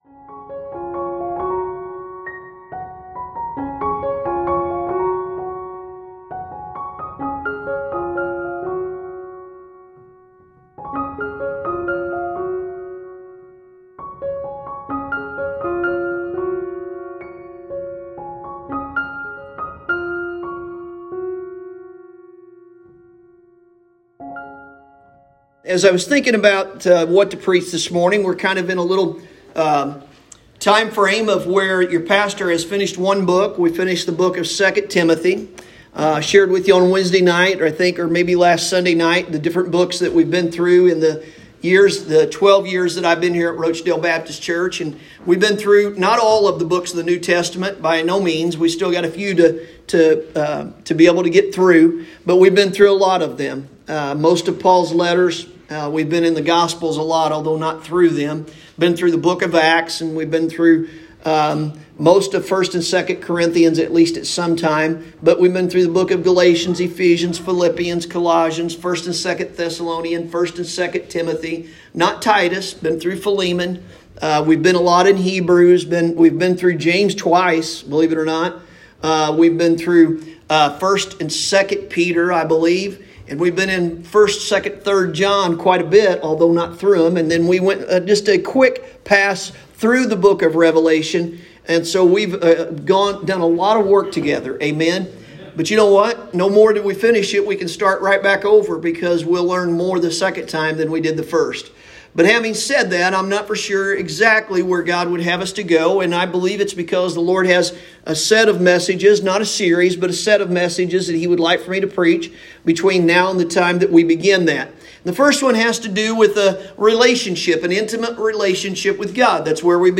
Sunday Morning – June 6th, 2021